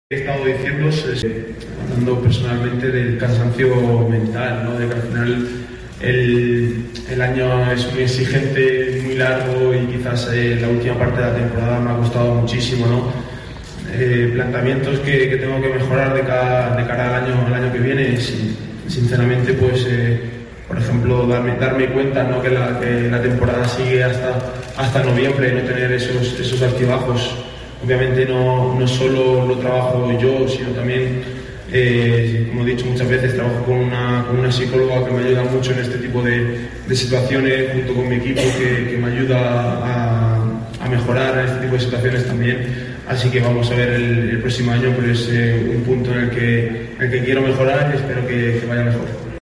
"Djokovic ha demostrado año tras año que está preparado para ganar los cuatro Gran Slams, pero aquí estamos nosotros para impedirlo junto a otros jugadores capaces de derrotarlo", declaró durante una rueda de prensa en la capital mexicana.